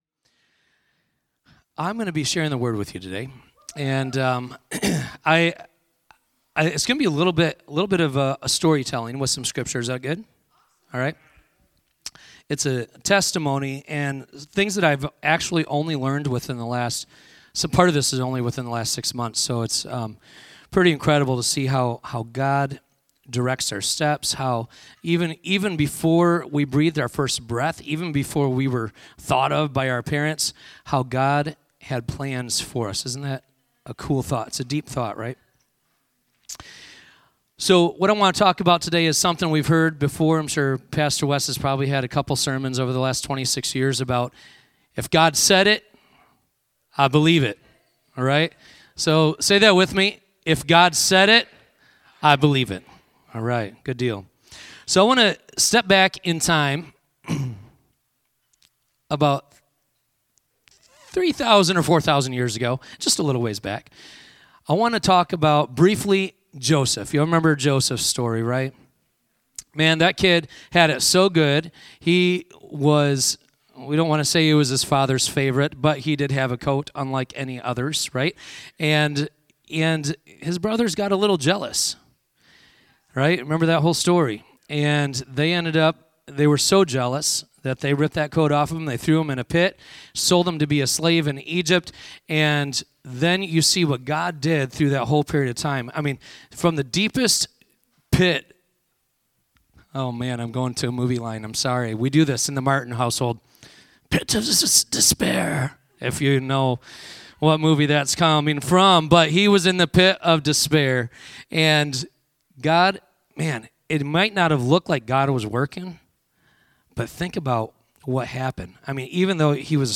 Sermon-6-15-25.mp3